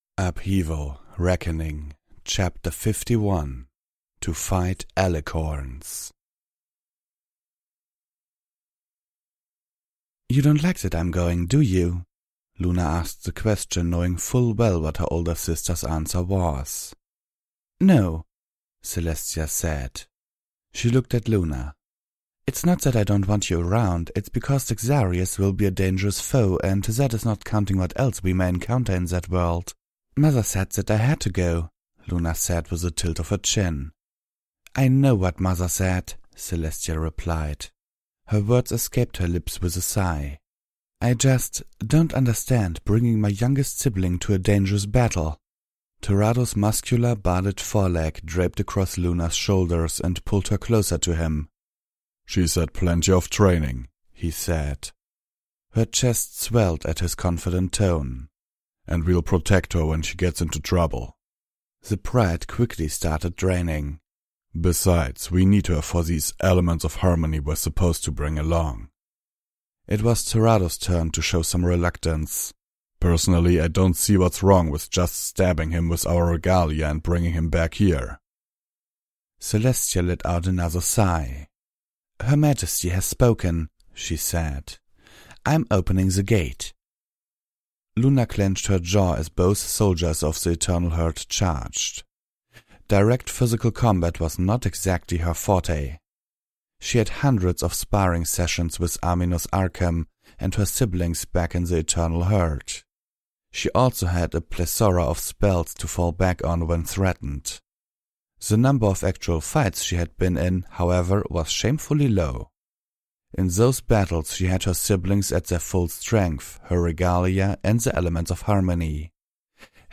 Audiobooks